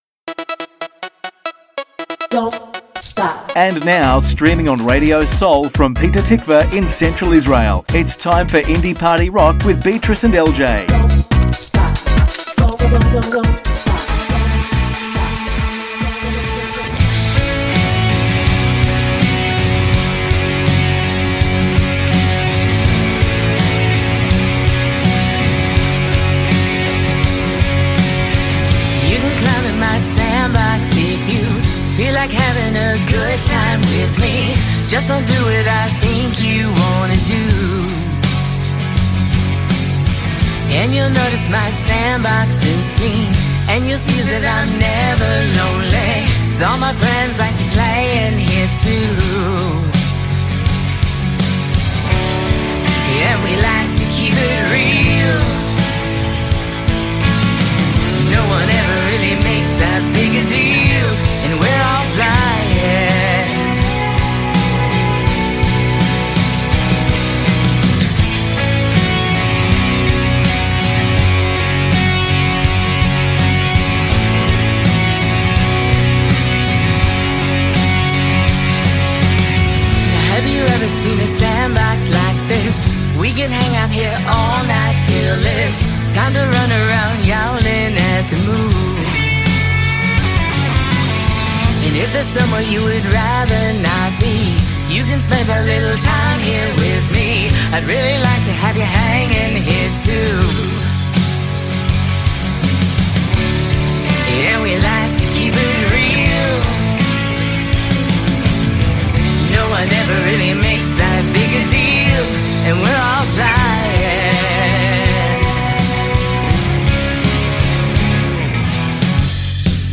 מוזיקת קאנטרי ואינדי עולמית - התכנית המלאה 28.12.24